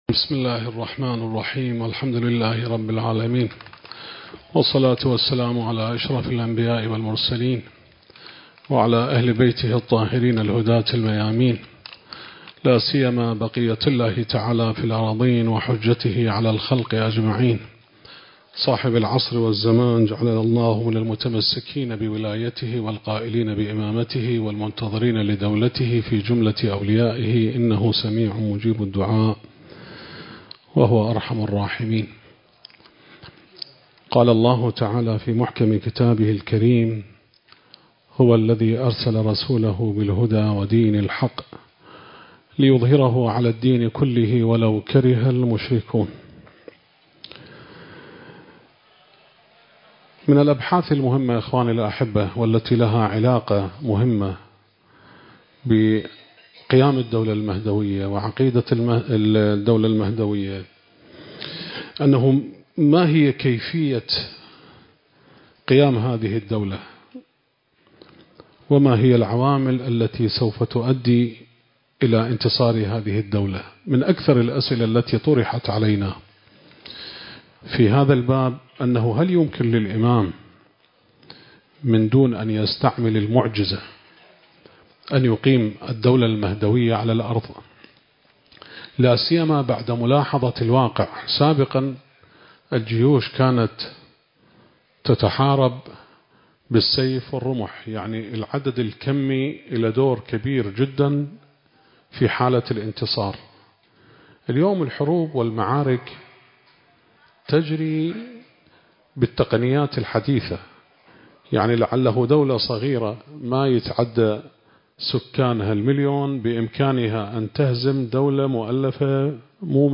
المكان: جامع وحسينية أهل البيت (عليهم السلام) / بغداد التاريخ: 2025